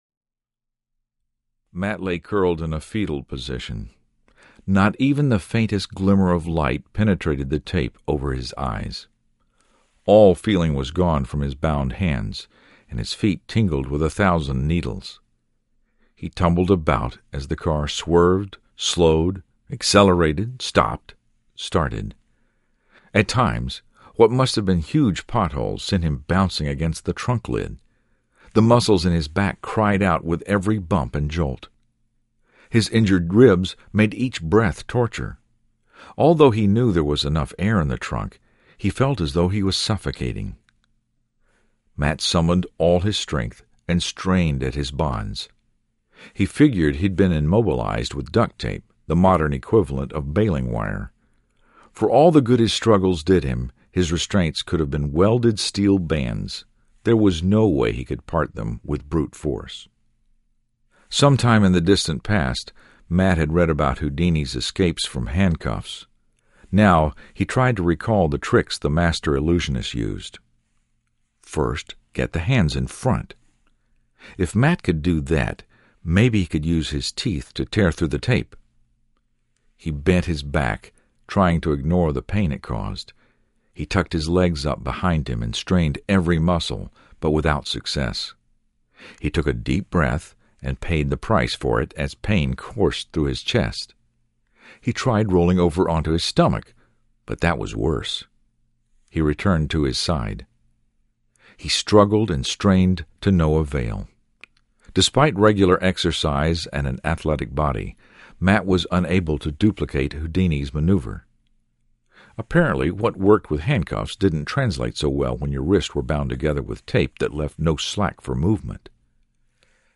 Stress Test Audiobook
8.7 Hrs. – Unabridged